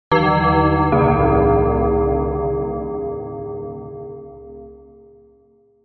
dark bell
bell creepy ding dong horror scary sound effect free sound royalty free Sound Effects